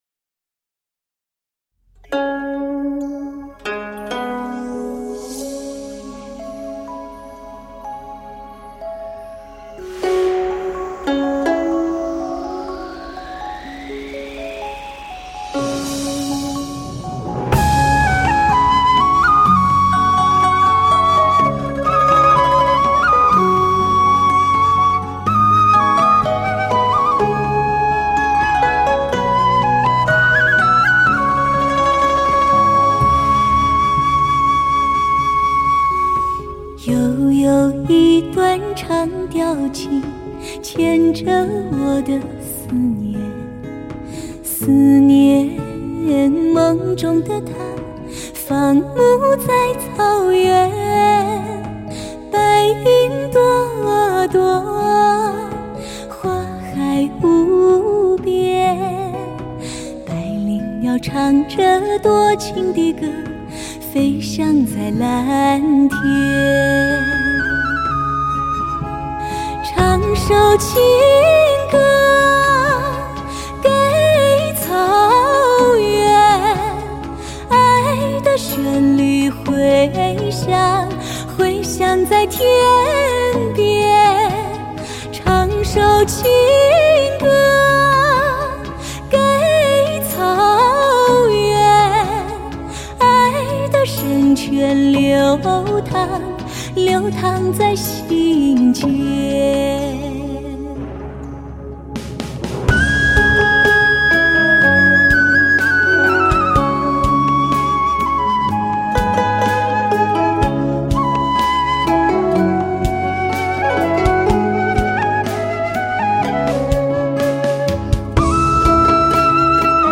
发烧界最为自然的极品天碟，演绎青春活力与激情，倾听如诗如梦幻般的天籁之声。
突发而至的清亮旋律，就如钻出云雾赫然而出的雪山和草原，
人声的婉转起伏与乐器的遥相呼应，让你有一种光芒四射的阳光打在头顶的感觉。